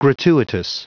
Prononciation du mot gratuitous en anglais (fichier audio)
Prononciation du mot : gratuitous